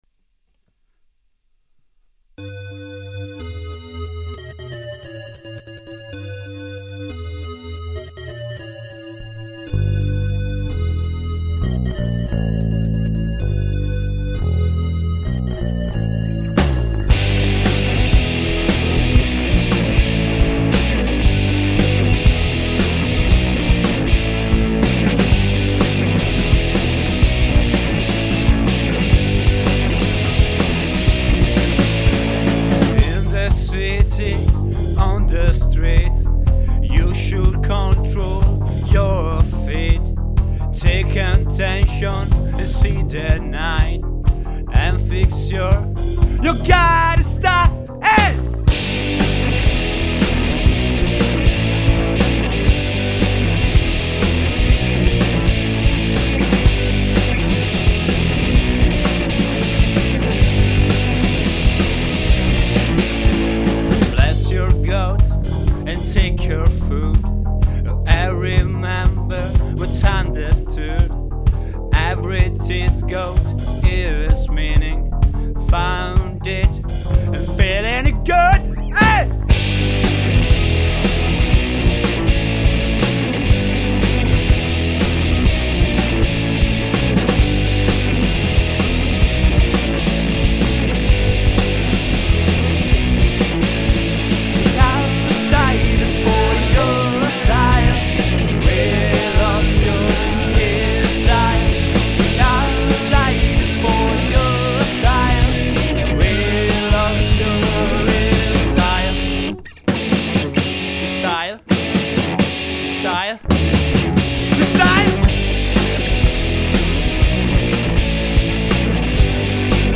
Luogo esecuzioneZeta Factory, Carpi (MO)
GenereRock